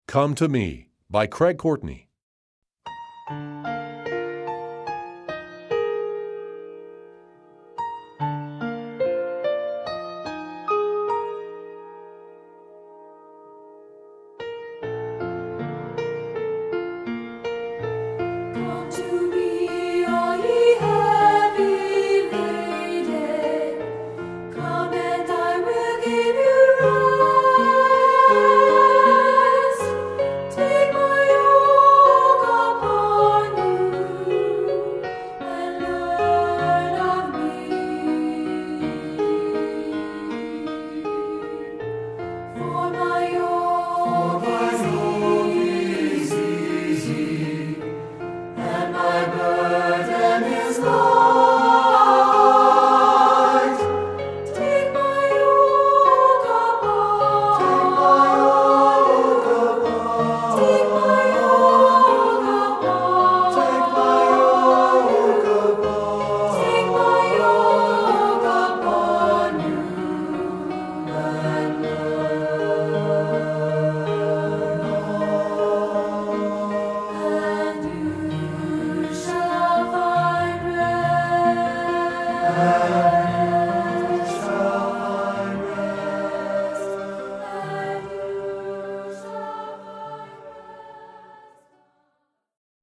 Voicing: 2-Part Mixed